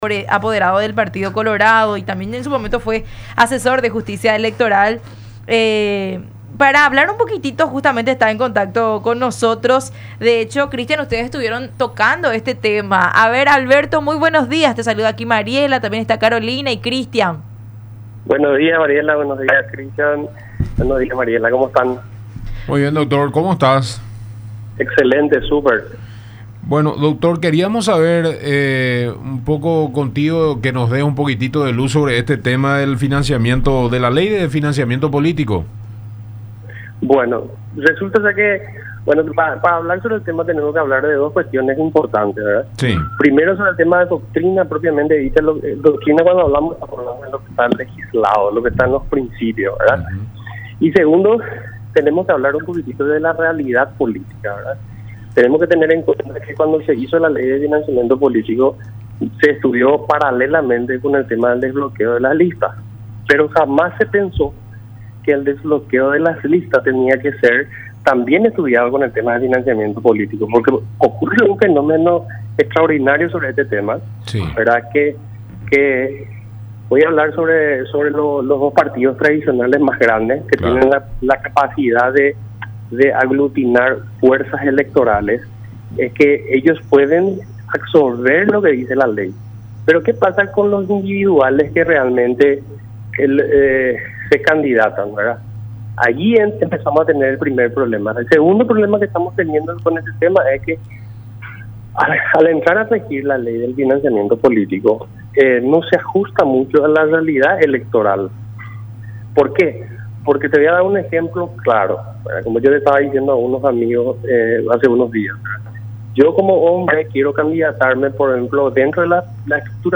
“Los empresarios entran a la política para cuidar sus negocios, no para ser una ayuda a la comunidad”, dijo en el programa “La Mañana De Unión” por Unión Tv y Radio La Unión.